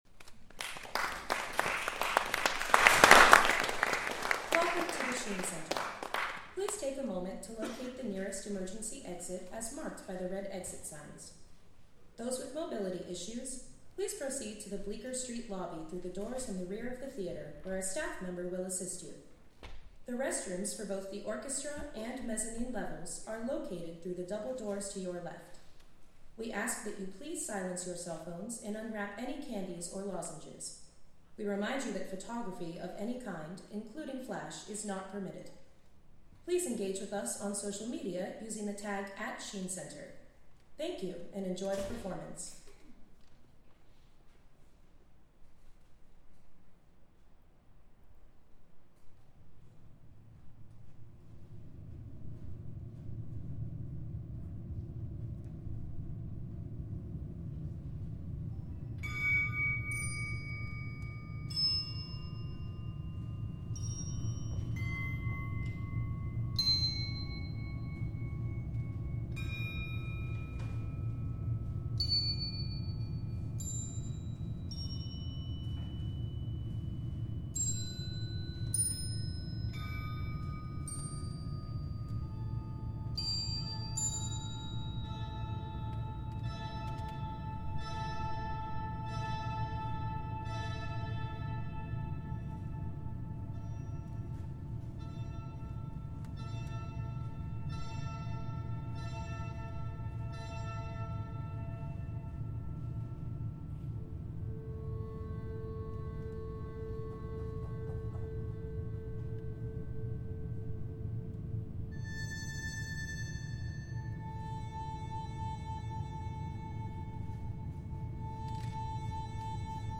Live from the International Computer Music Conference
Recorded from the Wave Farm Transmit Parter stream.